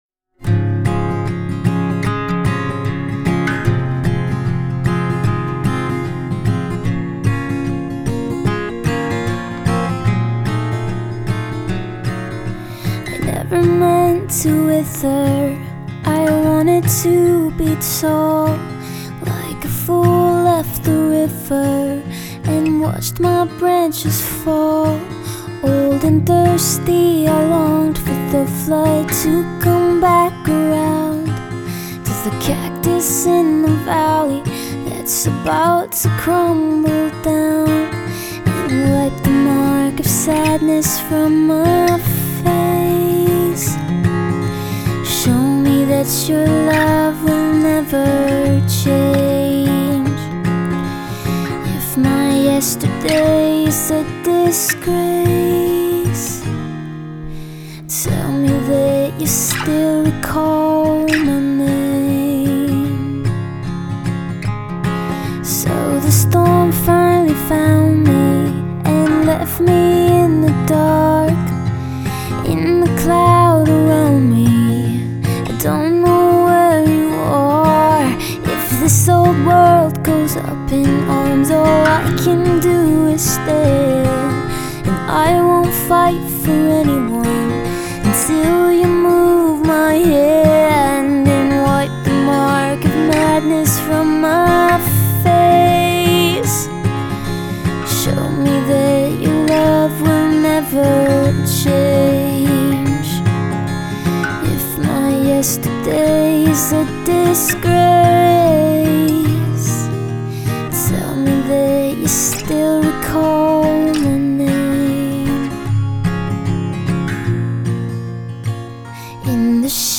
CactusInTheValleyAcoustic.mp3